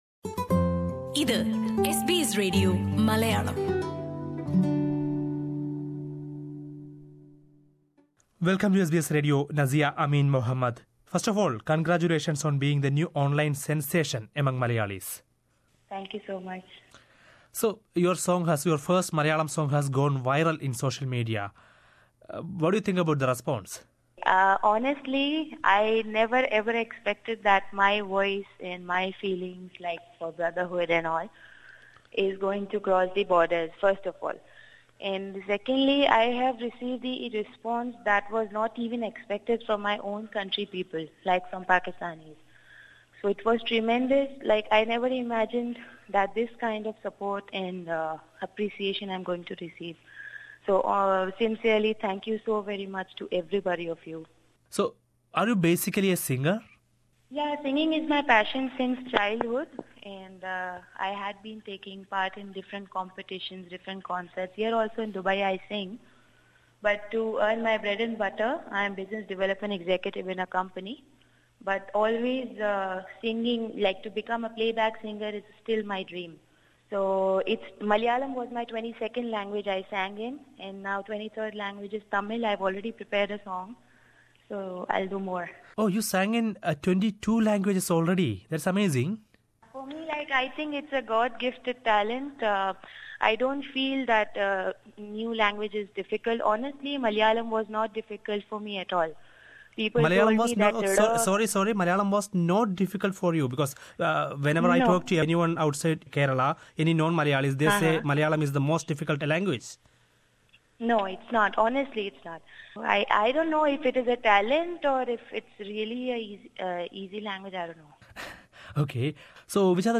എസ് ബി എസ് മലയാളം റേഡിയോയുമായുള്ള അഭിമുഖത്തിൽ